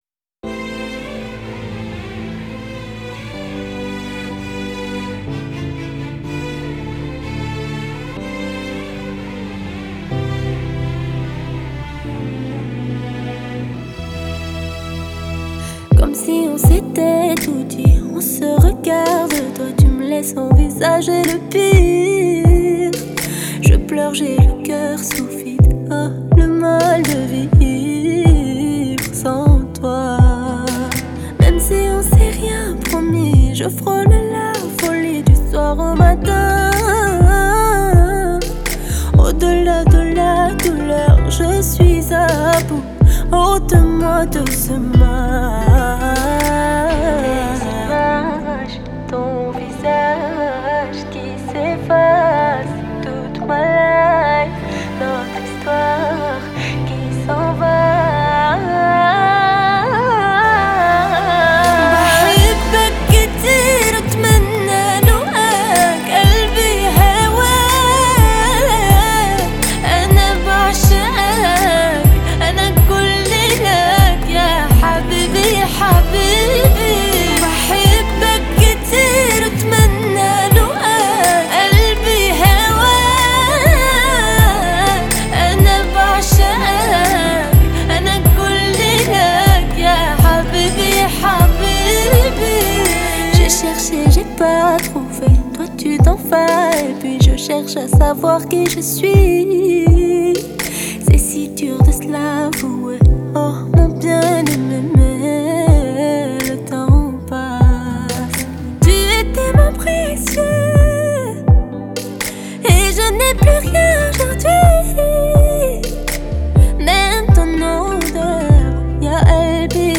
raï, moroccan pop, moroccan chaabi Écouter sur Spotify